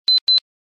alarm_03.ogg